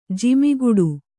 ♪ jimiguḍu